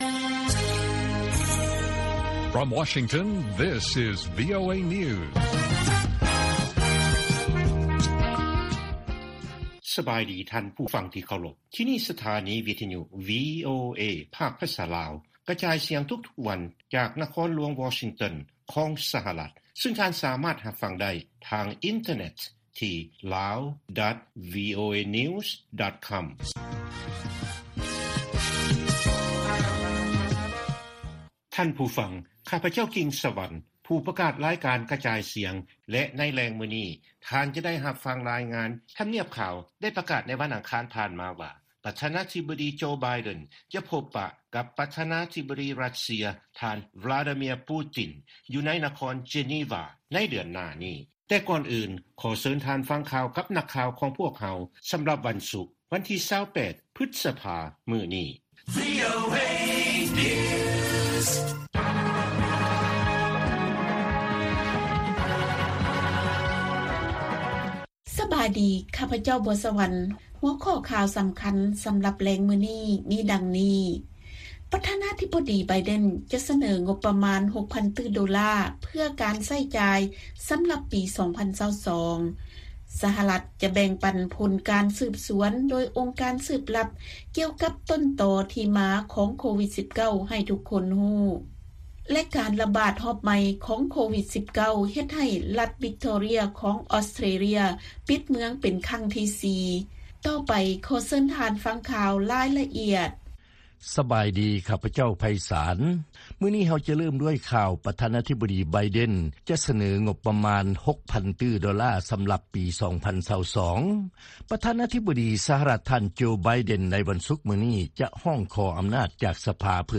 ລາຍການກະຈາຍສຽງຂອງວີໂອເອລາວ: ຄົນລາວຍັງຖືກຫ້າມເດີນທາງເຂົ້າ ສຫລ ນອກຈາກຜູ້ທີ່ເຂົ້າມາໃນນາມທາງການເທົ່ານັ້ນ
ວີໂອເອພາກພາສາລາວກະຈາຍສຽງທຸກໆວັນ. ຫົວຂໍ້ຂ່າວສໍາຄັນໃນມື້ນີ້ມີ: 1) ຄົນລາວຍັງຖືກຫ້າມເດີນທາງ ເຂົ້າ ສຫລ ນອກຈາກຜູ້ທີ່ເຂົ້າມາໃນນາມທາງການເທົ່ານັ້ນ.